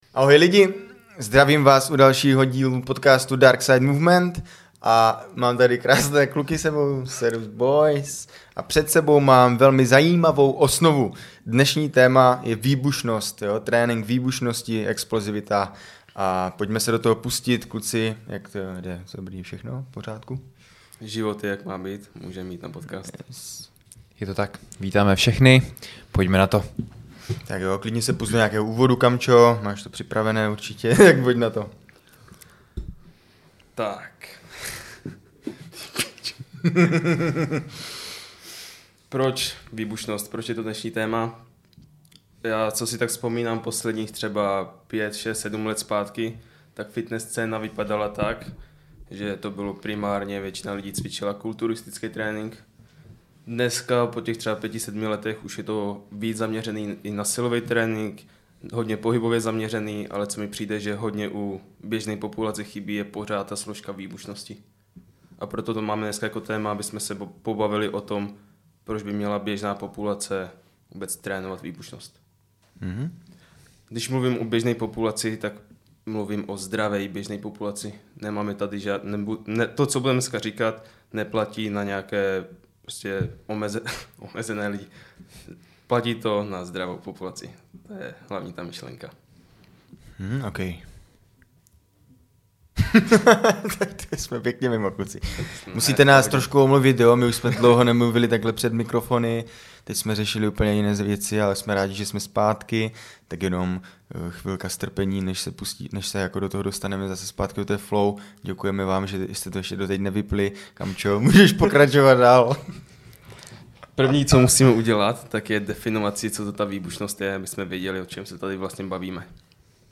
To vše a mnohem více v tomto díle, kde panovala velmi uvolněná atmosféra.